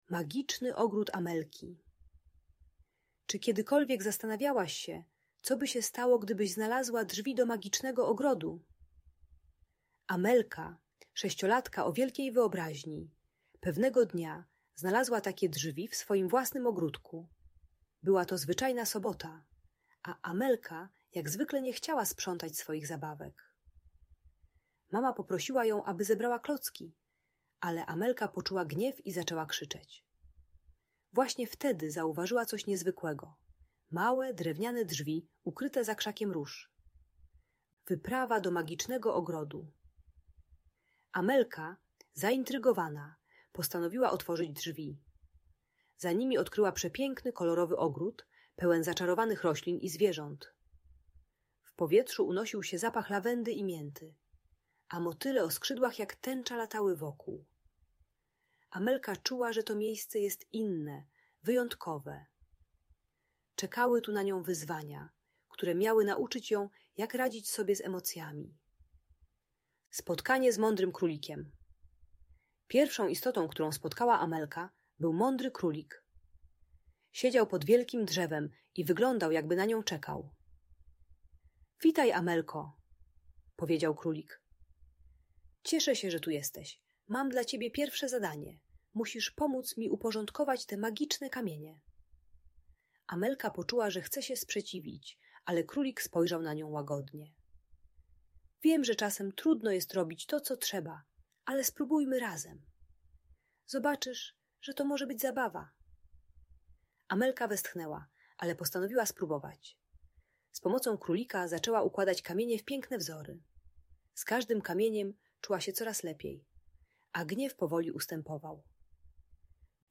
Magiczny Ogród Amelki - Audiobajka